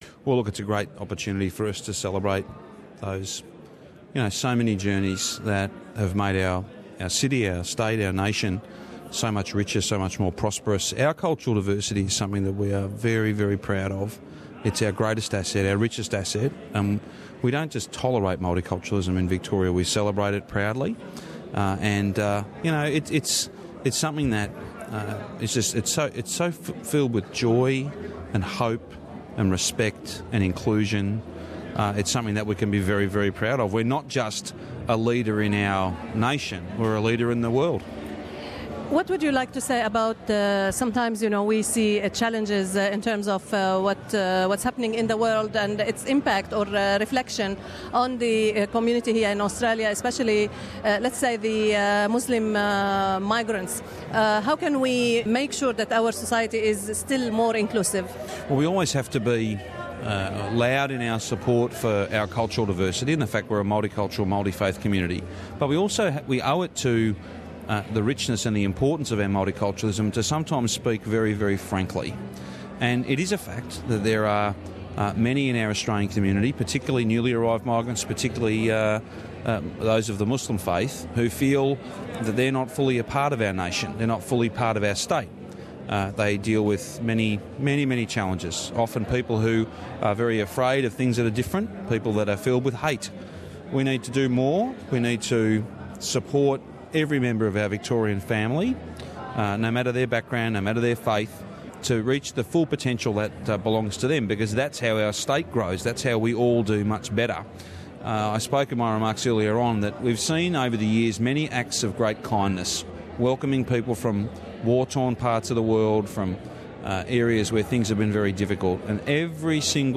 During the Premier's Gala Dinner, a yearly event that celebrates cultural diversity in the State of Victoria, we spoke to the Victorian Premier the Honorable Daniel Andrews. Mr Andrews reaffirmed that multiculturalism and inclusion are two aspects that help protecting our society. He said that Victoria is willing to extend its welcoming arms to the new refugees from Syria when they arrive to Australia.